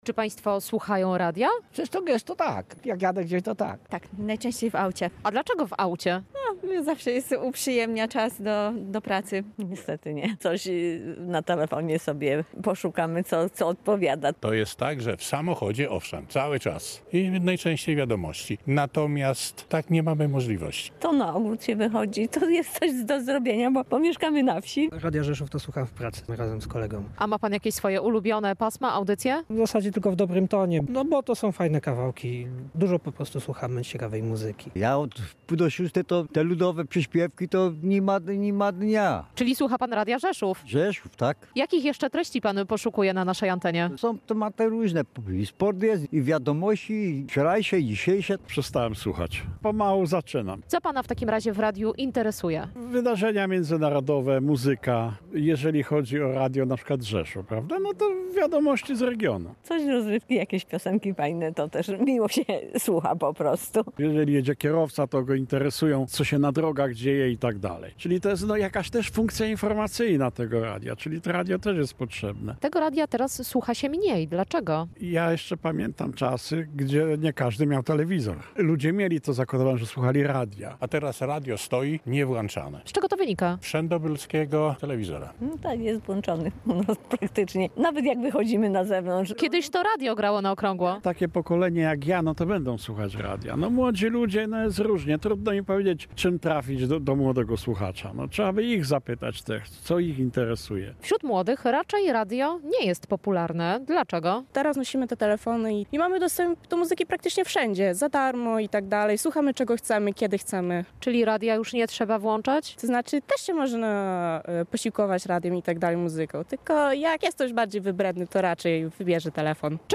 sonda-radiontitled.mp3